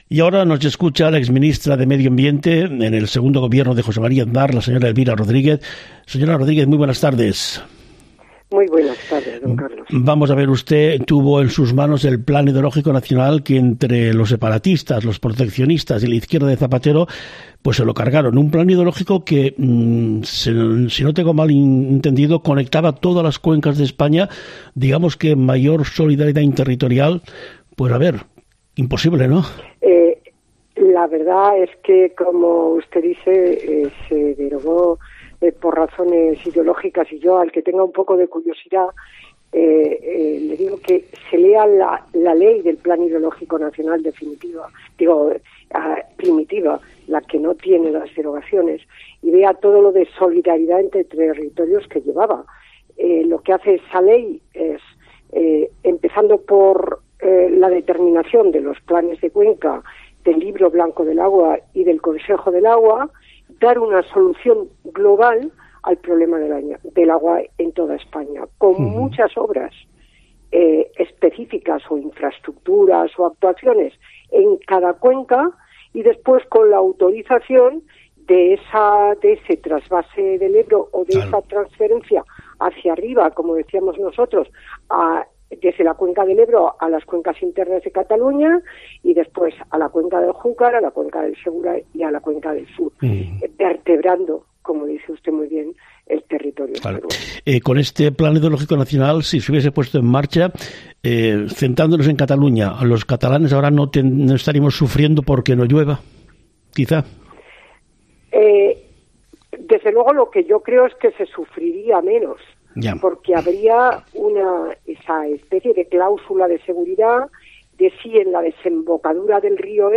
En una reciente entrevista, la exministra de Medio Ambiente durante el segundo Gobierno de José María Aznar, Elvira Rodríguez , destacó la oportunidad perdida con la derogación del Plan Hidrológico Nacional.